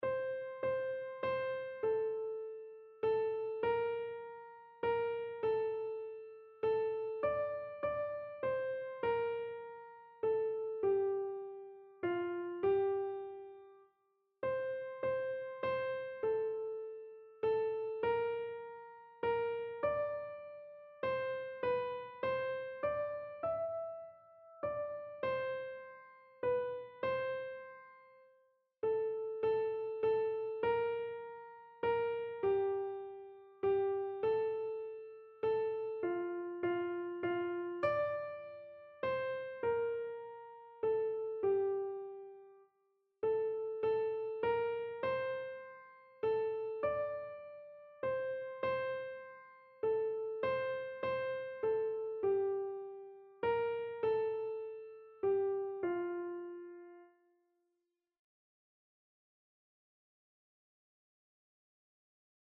Einzelstimmen (Unisono)
• Sopran [MP3] 978 KB